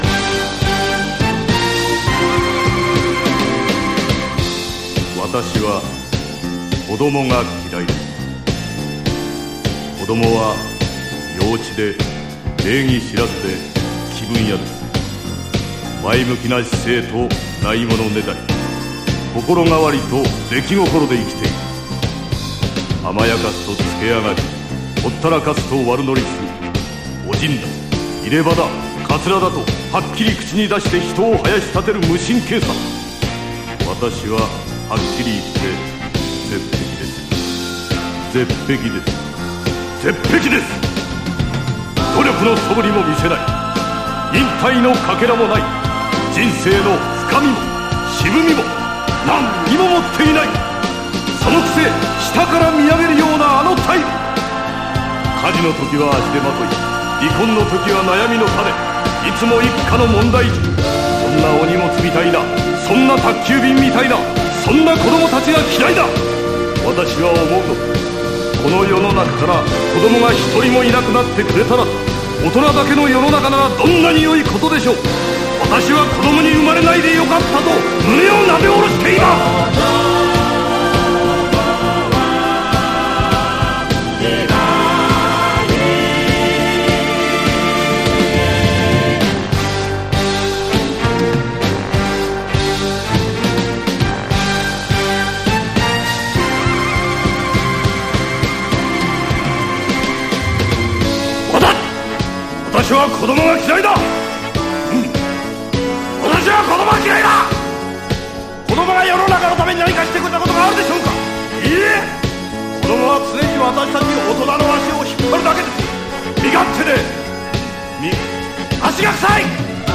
和ニューウェイヴ/テクノ歌謡迷作！
令和の時代にはより一層そぐわない辛辣なメッセージ、鬼気迫る怒号に圧倒される問題作。